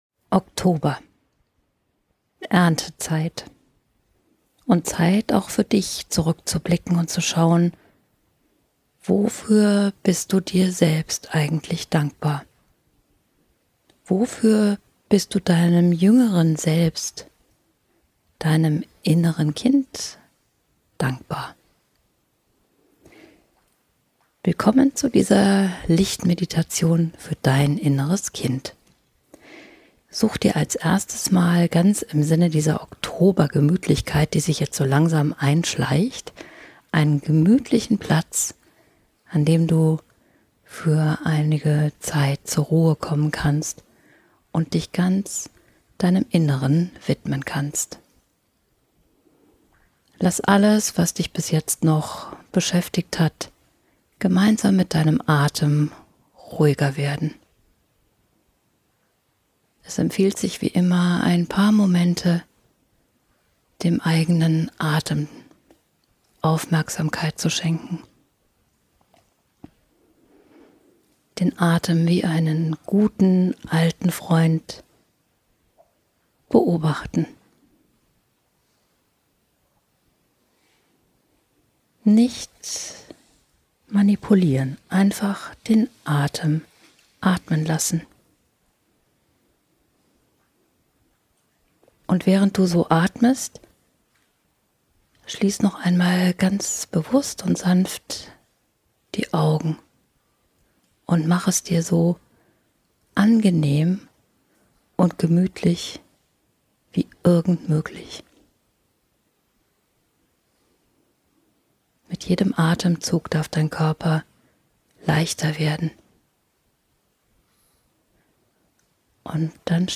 Erntedankfest für dich und dein Inneres Kind – eine Lichtmeditation ~ Lichtnetz-Meditationen Podcast